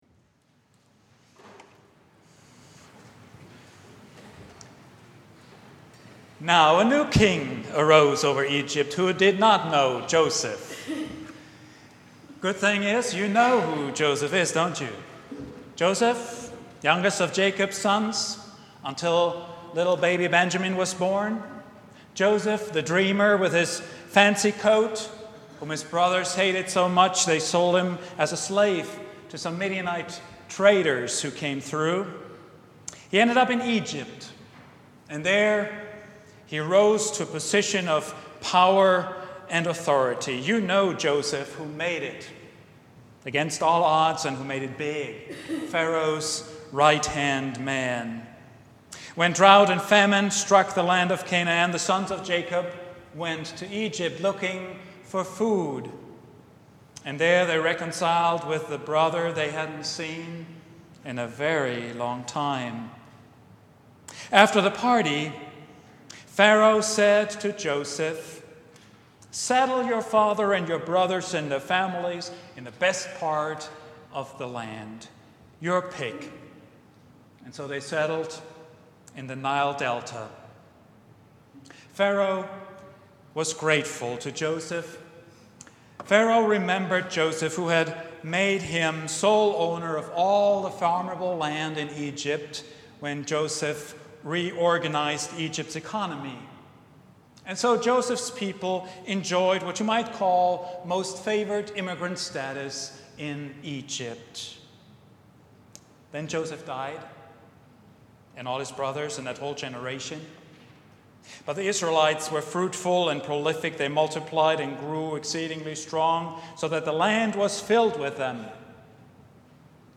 The Shadow of Pharaoh — Vine Street Christian Church